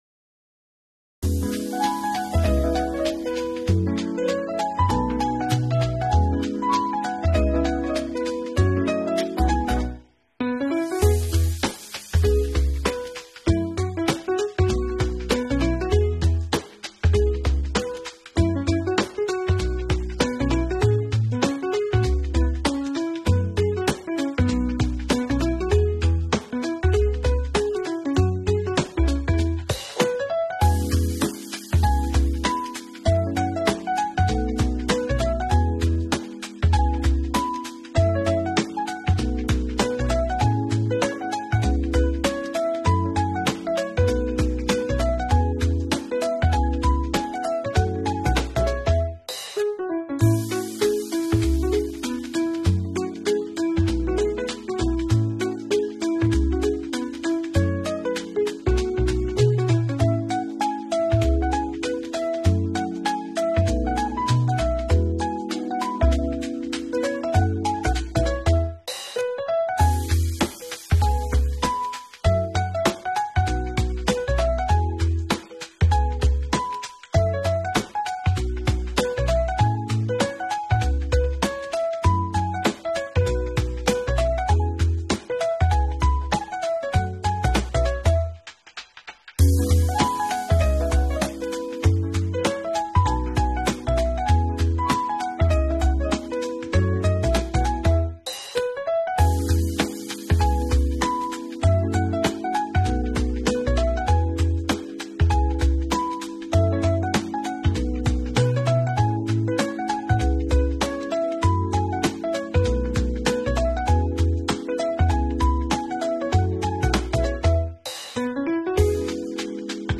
Jazz Fusion BGM